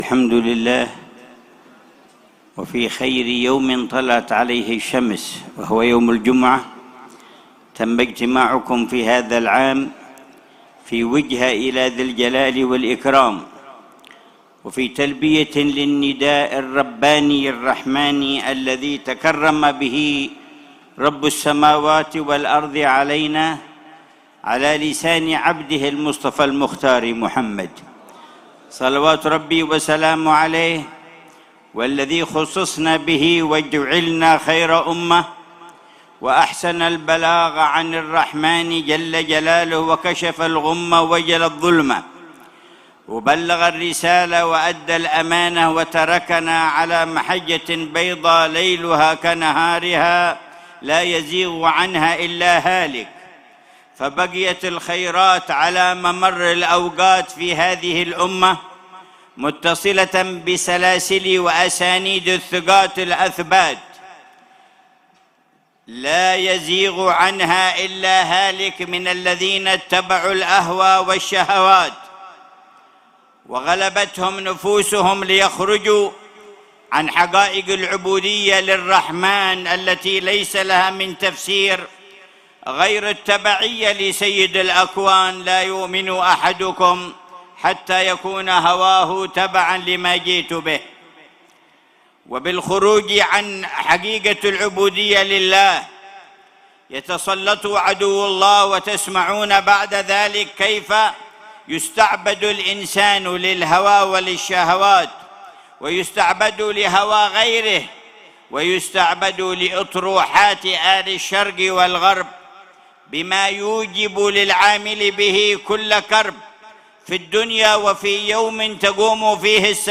كلمة الحبيب عمر بن محمد بن حفيظ في زيارة المشهد السنوية مع ذكرى المولد النبوي الشريف، في مشهد الحبيب علي بن حسن العطاس، الهجرين، وادي حضرموت.